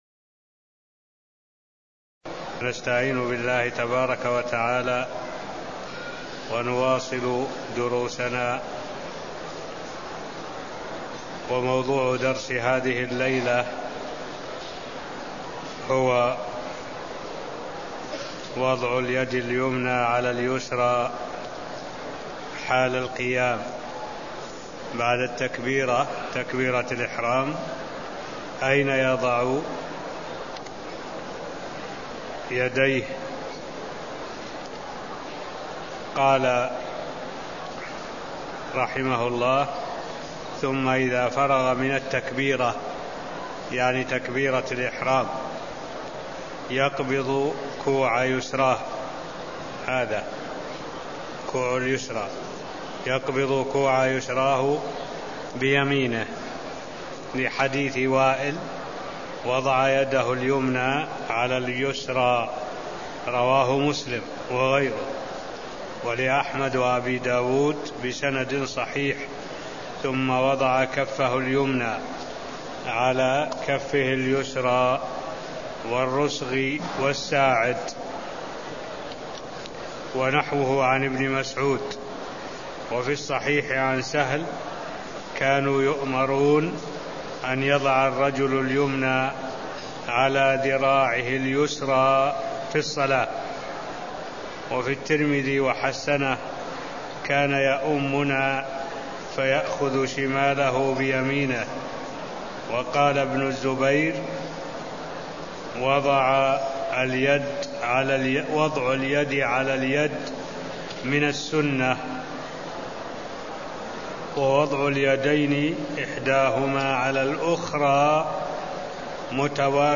المكان: المسجد النبوي الشيخ: معالي الشيخ الدكتور صالح بن عبد الله العبود معالي الشيخ الدكتور صالح بن عبد الله العبود باب-صفة الصلاة ص40 (0031)